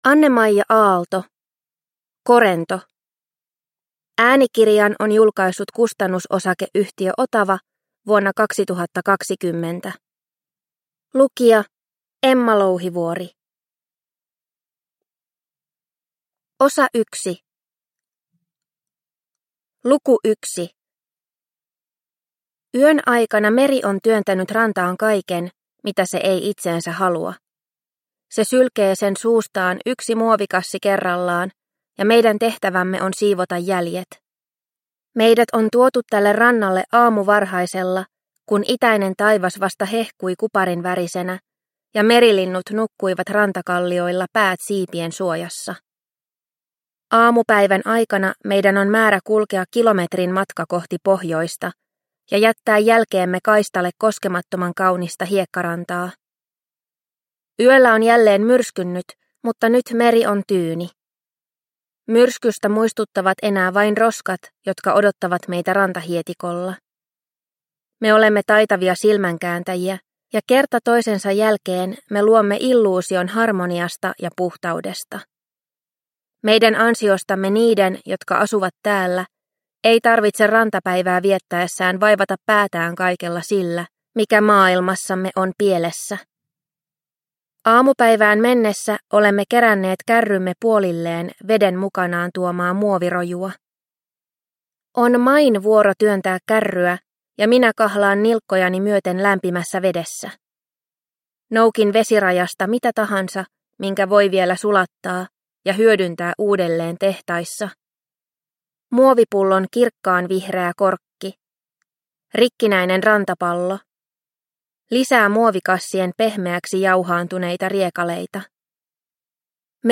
Korento – Ljudbok – Laddas ner